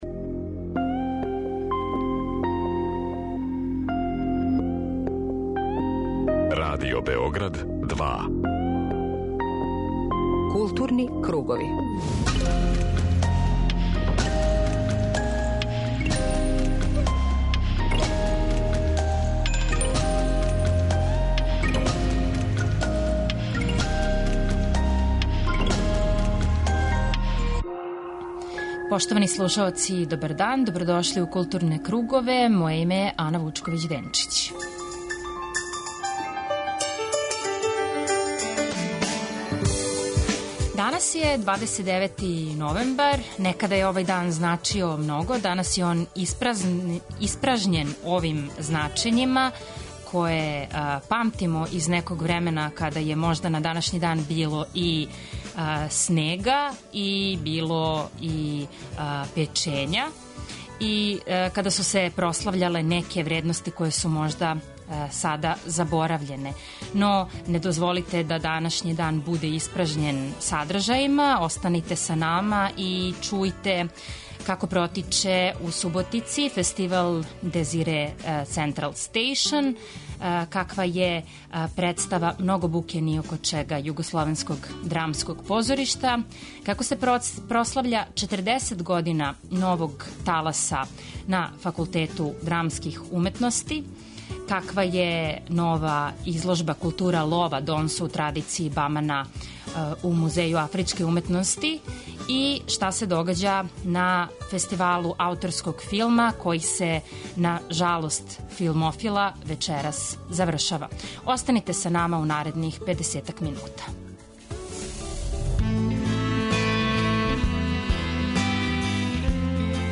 Магазин културе